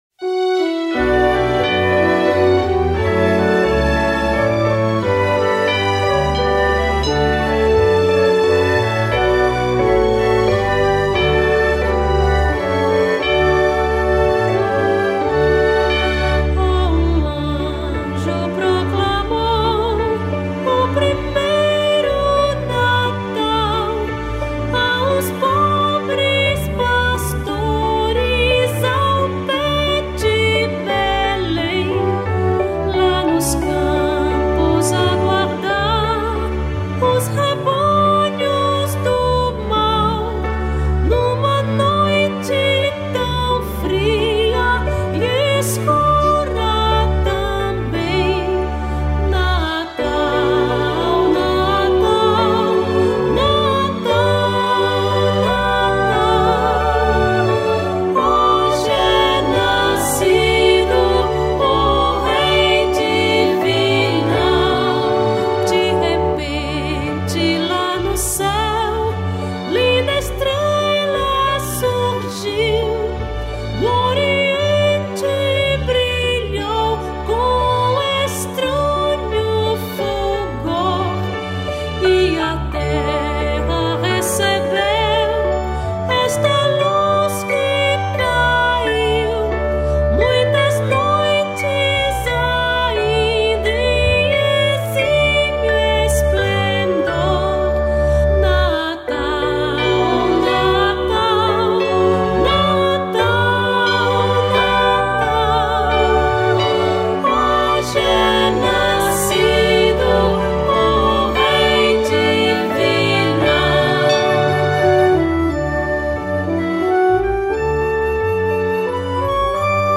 1052   03:38:00   Faixa:     Canção Religiosa